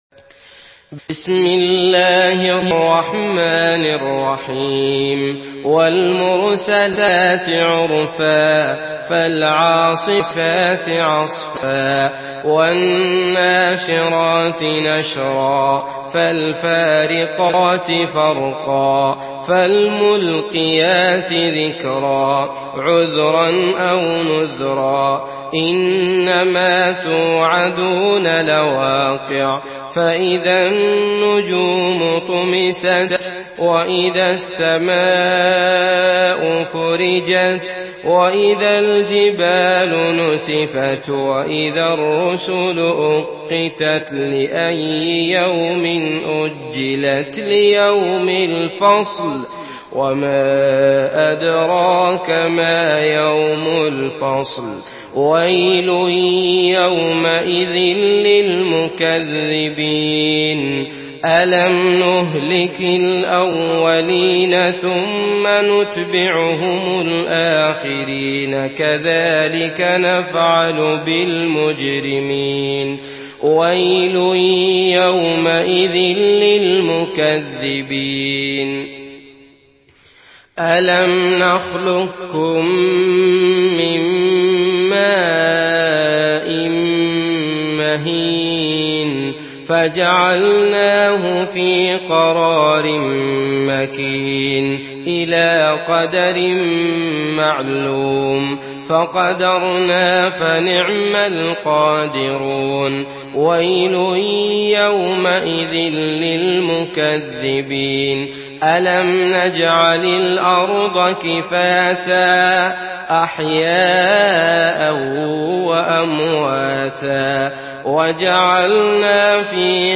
سورة المرسلات MP3 بصوت عبد الله المطرود برواية حفص
مرتل